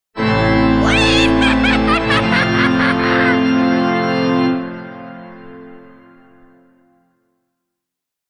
Scary Organ With Laugh Sound Effect
A spooky and dramatic church organ sound combined with an eerie, evil laugh.
Genres: Sound Effects
Scary-organ-with-laugh-sound-effect.mp3